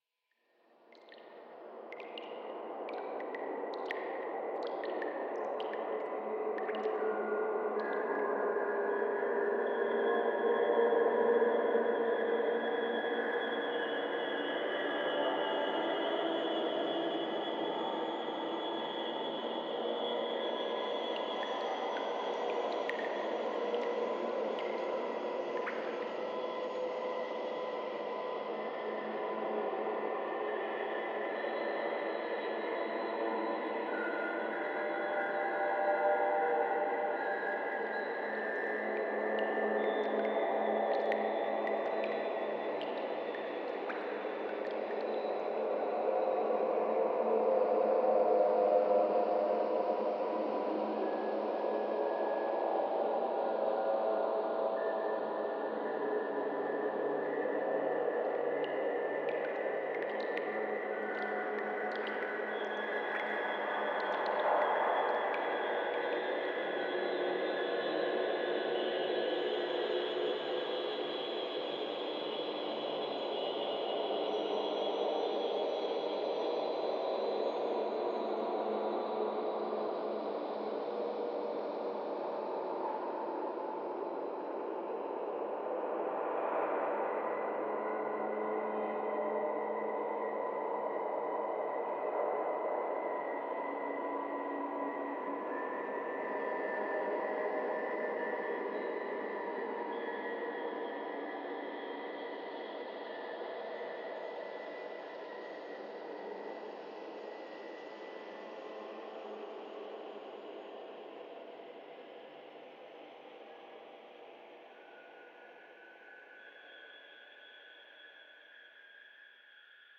09_裂缝空间_地下通道.ogg